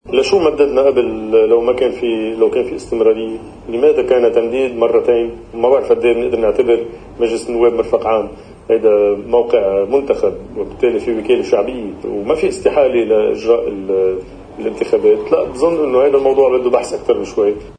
ردّ وزير الداخلية السابق زياد بارود على الرئيس بري الذي قال انه لا يحصل فراغ داخل مجلس النواب بل استمرارية، فقال له ضمن تقرير على قناة الـ”OTV”: